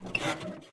carSuspension.ogg